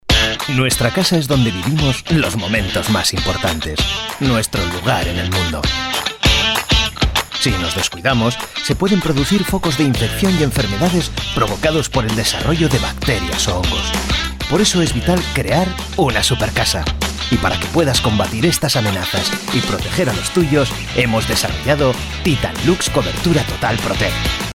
Native Voice Samples
Commercial Demo
Corporate Videos
High
AccurateArticulateAssuredAuthoritativeBelievableCalmConversationalCorporateDynamicEngagingKnowledgeableRelatable